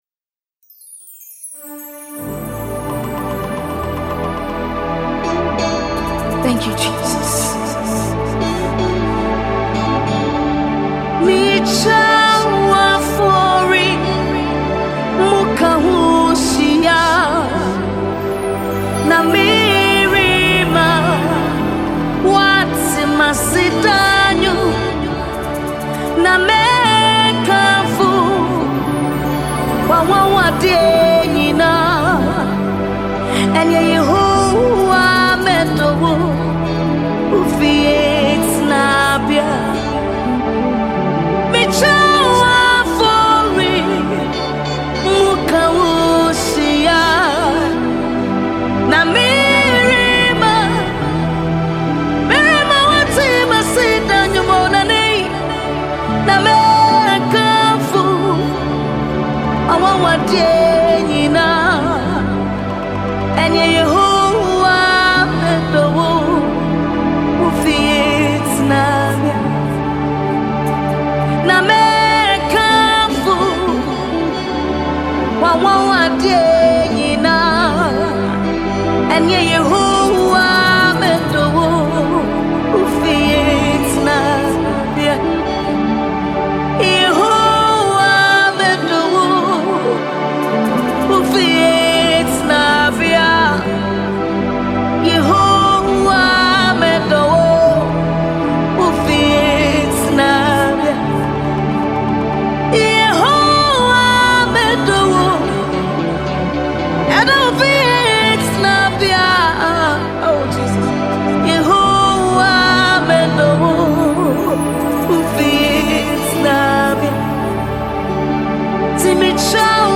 Are you looking for uplifting worship music?
Genre: Gospel